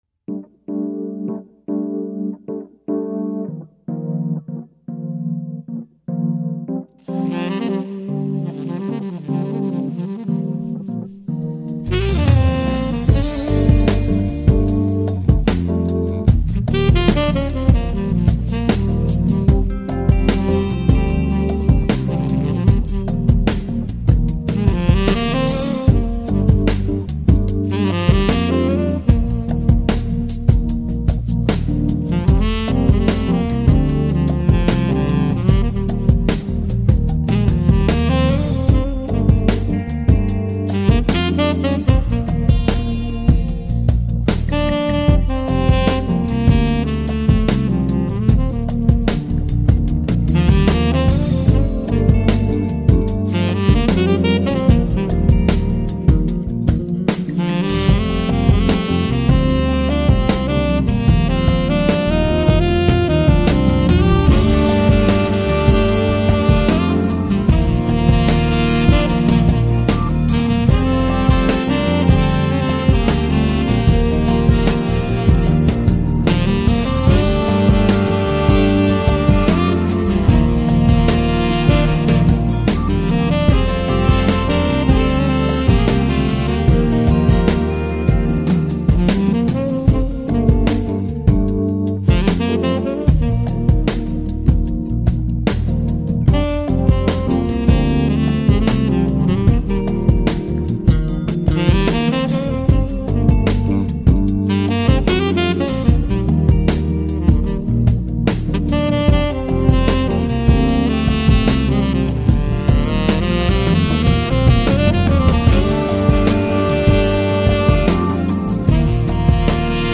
review